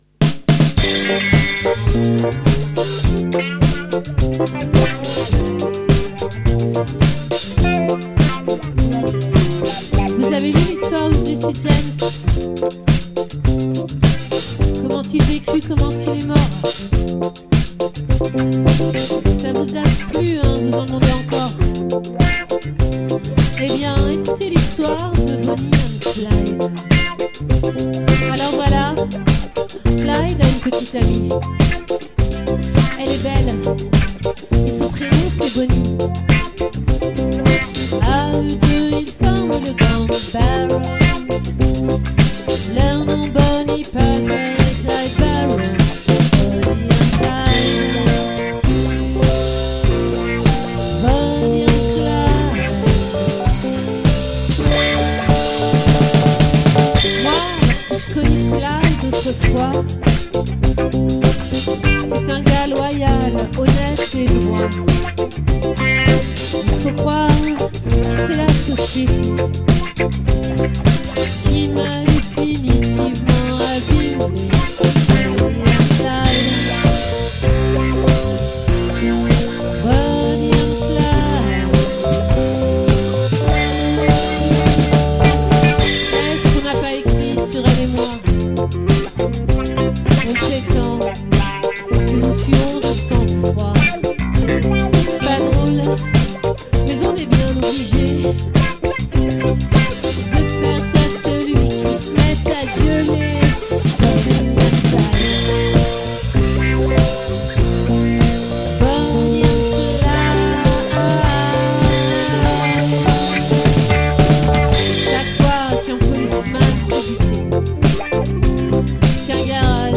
🏠 Accueil Repetitions Records_2023_01_04_OLVRE